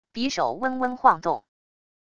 匕首嗡嗡晃动wav音频